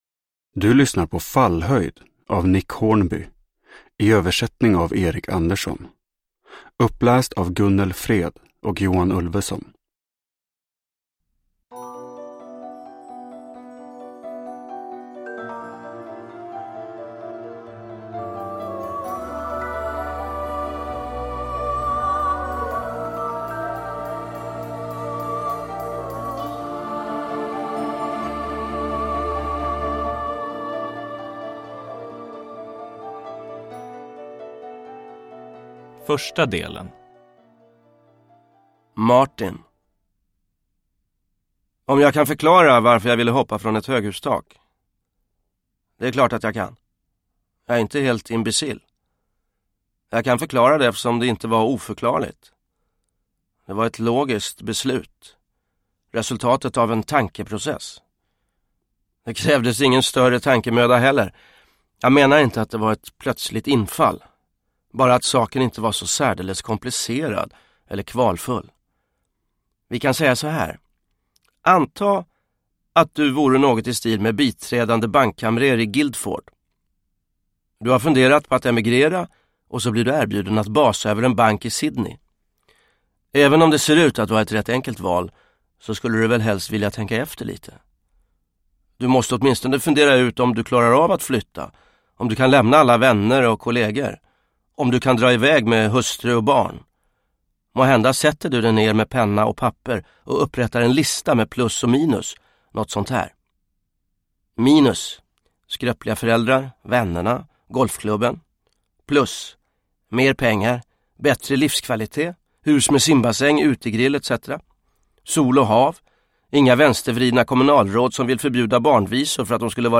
Fallhöjd – Ljudbok – Laddas ner
Uppläsare: Gunnel Fred, Johan Ulveson